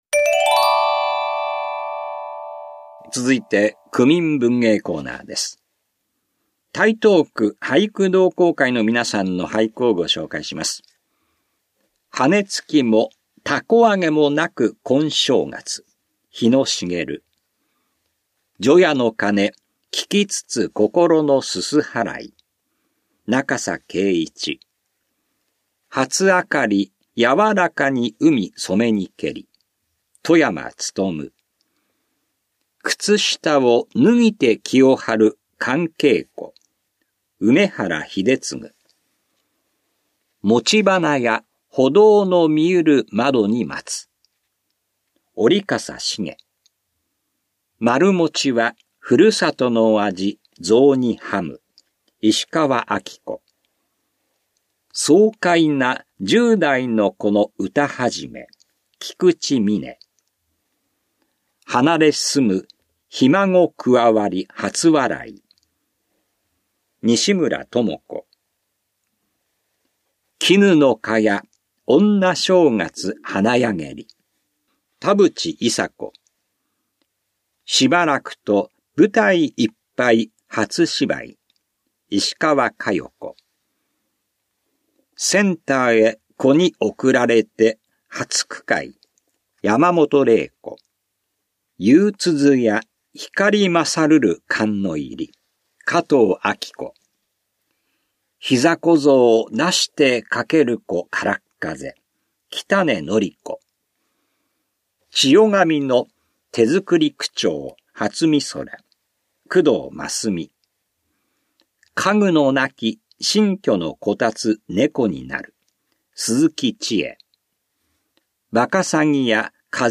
広報「たいとう」令和5年2月5日号の音声読み上げデータです。